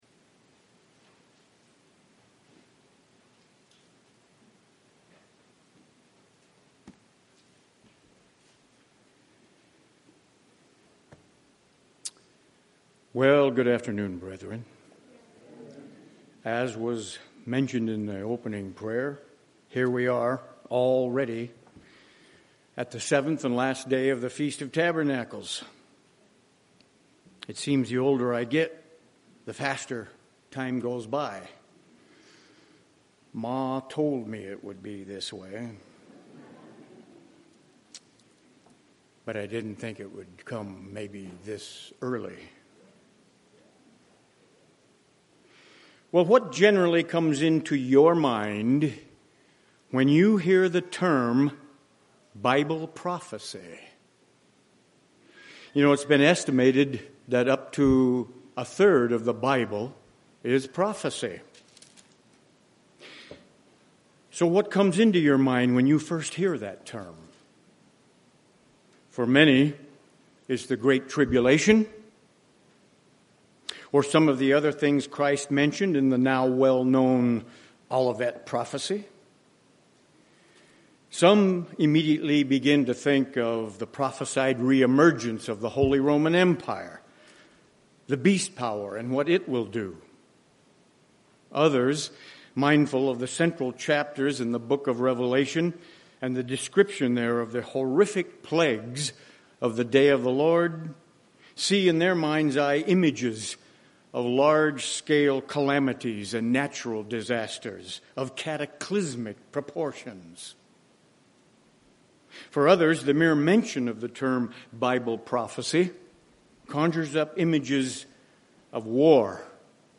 This sermon was given at the Phoenix, Arizona 2017 Feast site.